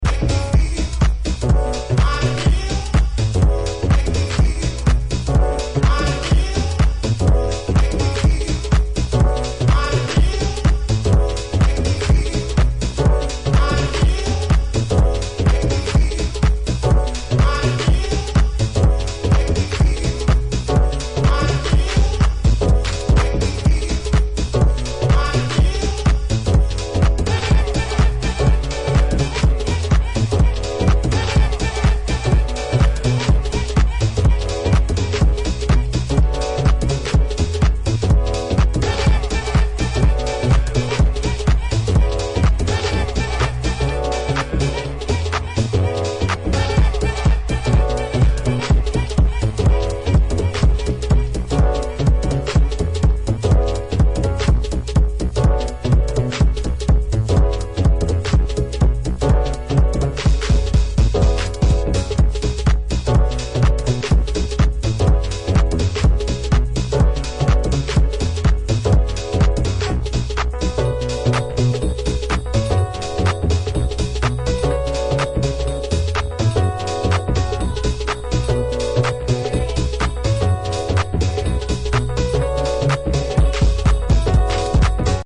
deep house classics